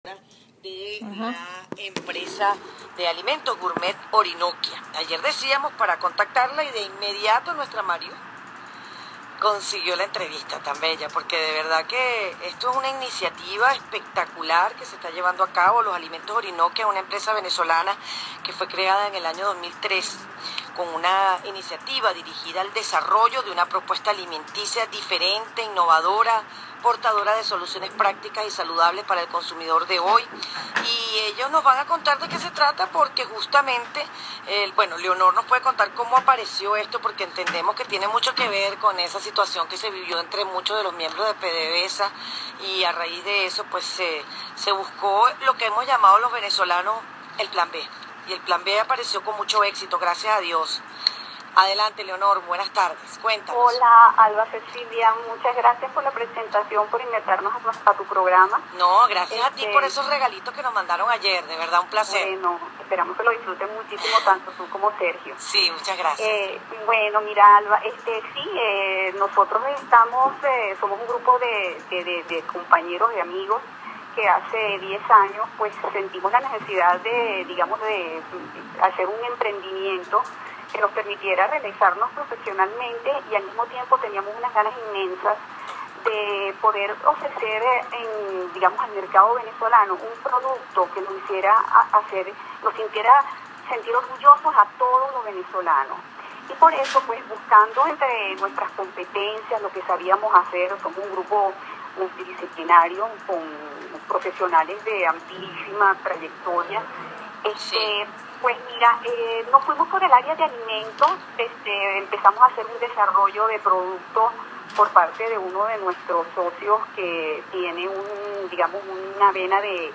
Entrevista por Radio con motivo del Decimo Aniversario